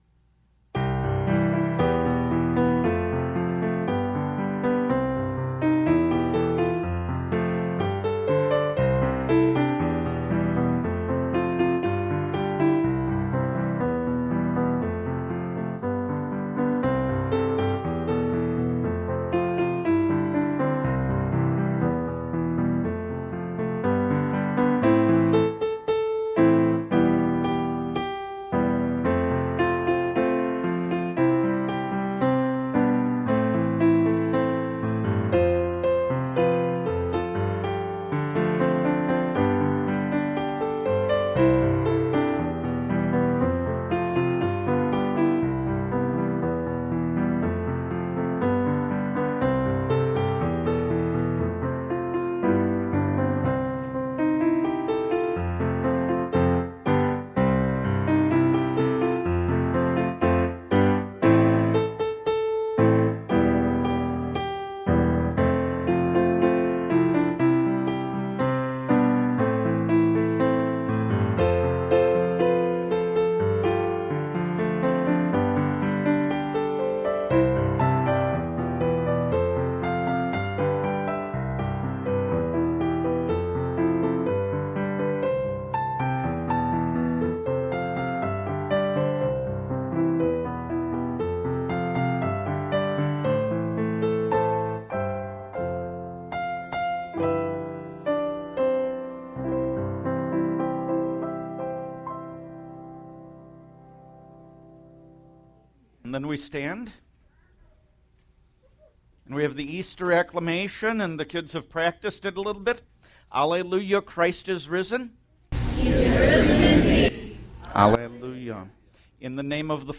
sermon 1-5-14